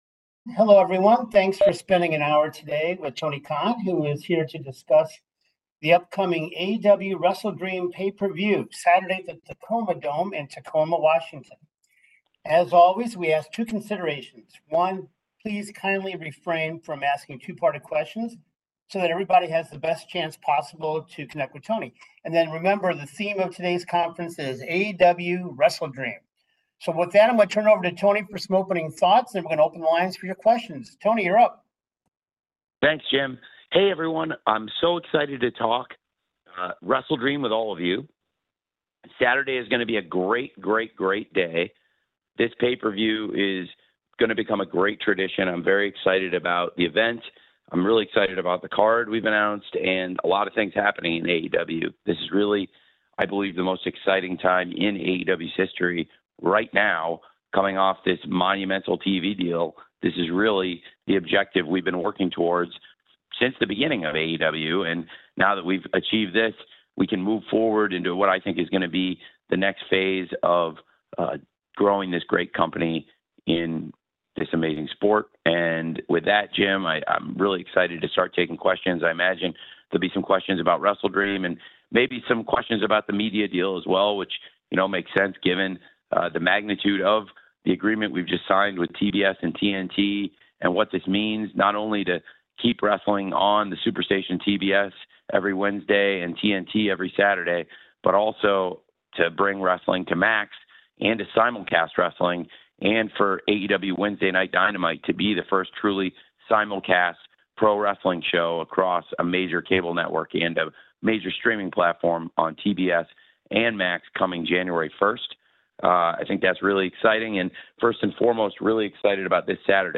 Tony Khan speaks with the media ahead of AEW WrestleDream 2024 taking place on Saturday, October 12, 2024 at the Tacoma Dome in Tacoma, WA.